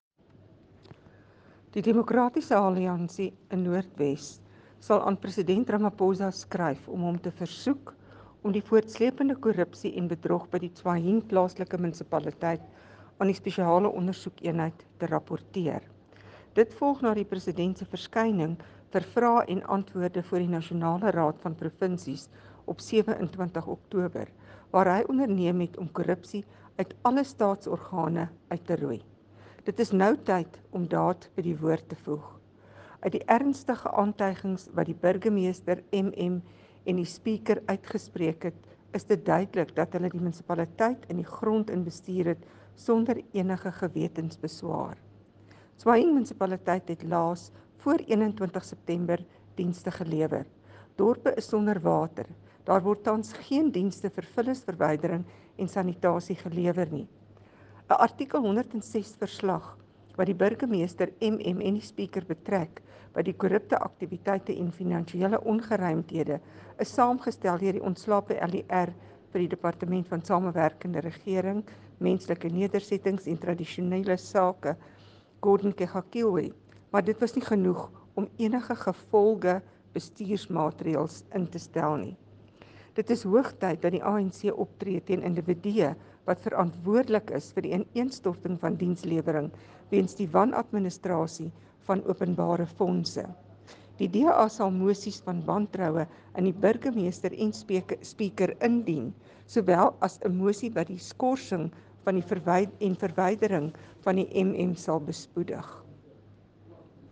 Note to Editors: Find attached soundbites in
Afrikaans by DA National Council of Provinces Delegate, Carìn Visser MP.